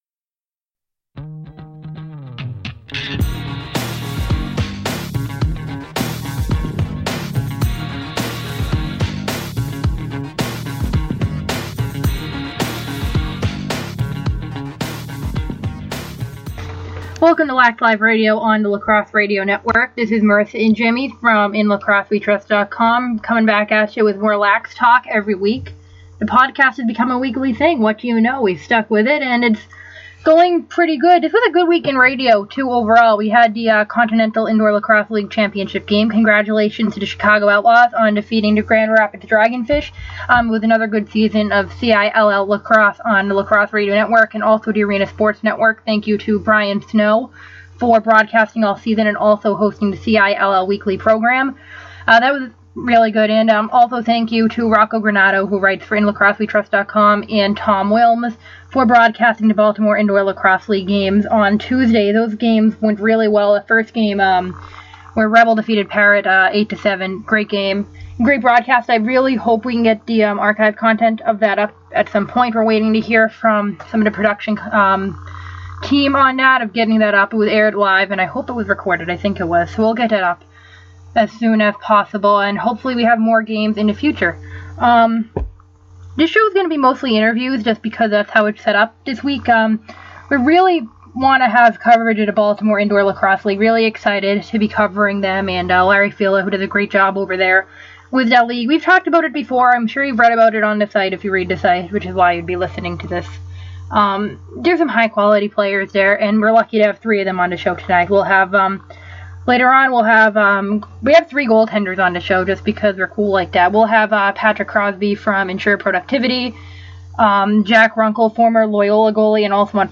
This week’s Lax Live Radio podcast is packed from interviews covering nearly every corner of the sport.